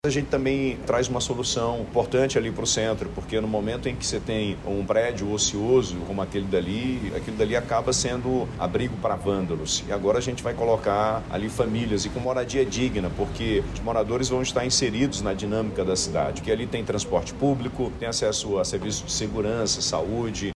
O governador do Amazonas, Wilson Lima, explica que o projeto possibilita a reocupação do Centro da capital amazonense.